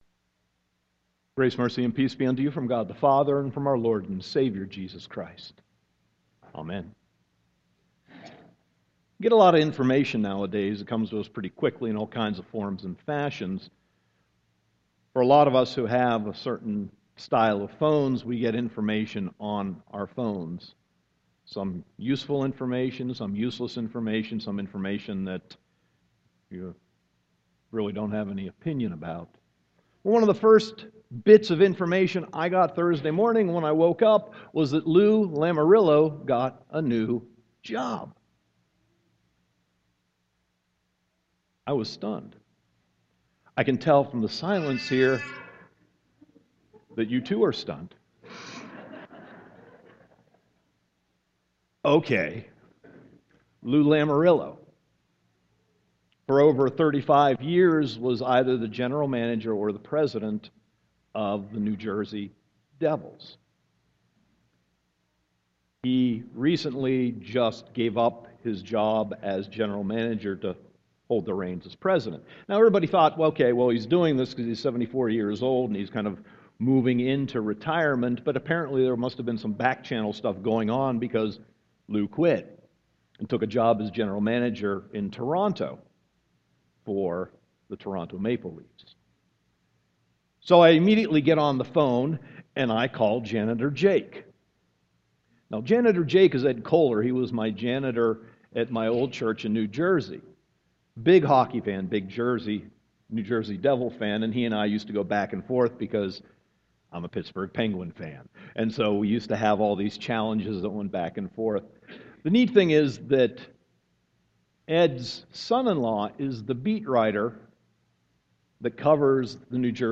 Sermon 7.26.2015